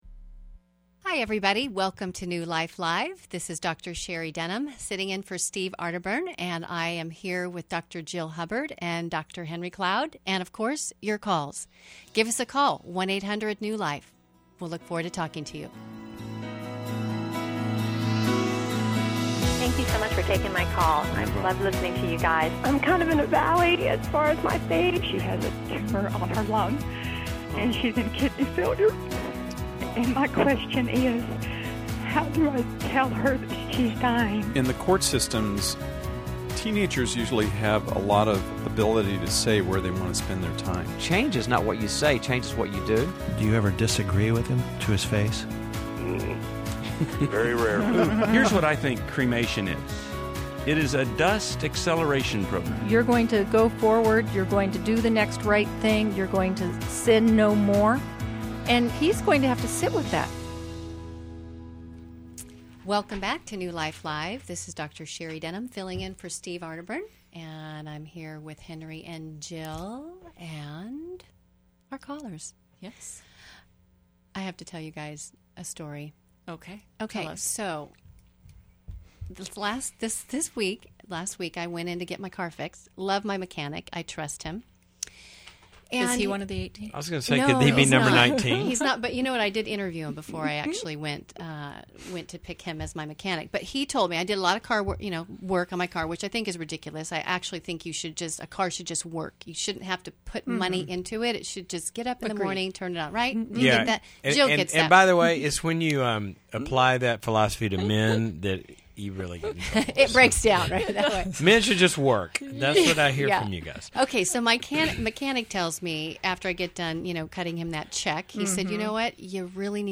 Caller Questions: 1.